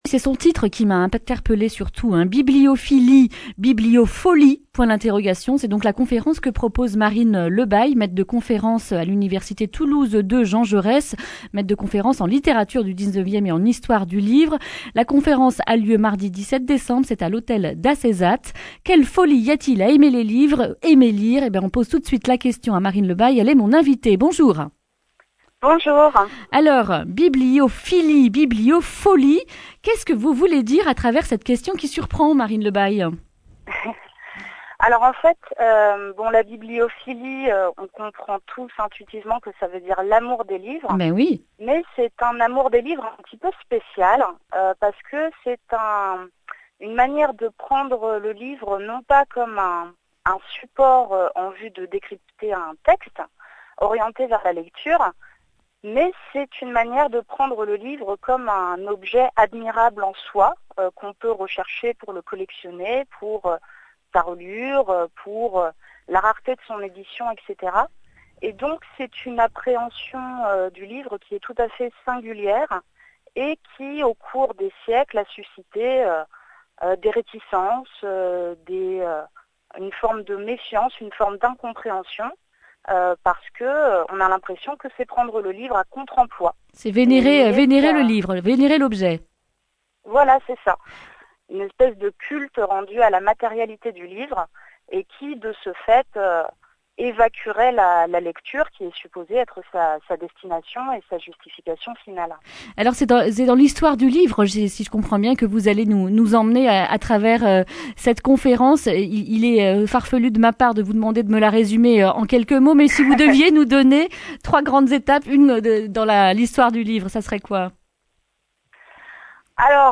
lundi 16 décembre 2019 Le grand entretien Durée 10 min